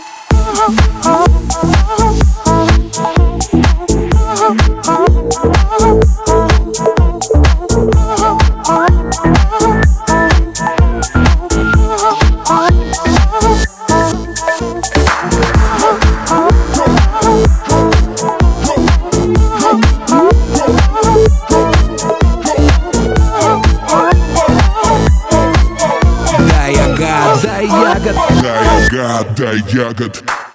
• Качество: 320, Stereo
веселые
спокойные
Неспешный бит в танцевальном треке